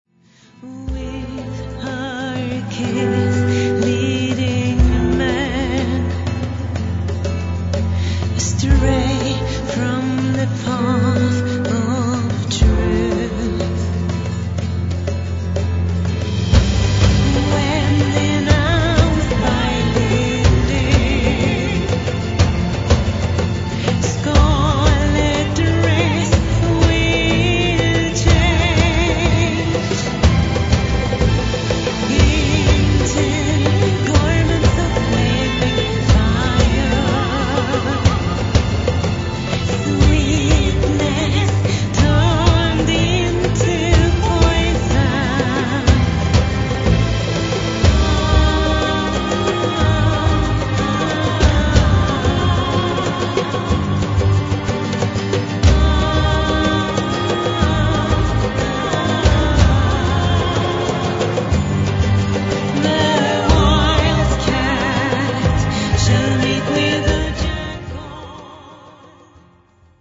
vocals
guitars
drums, percussions
keyboards, programming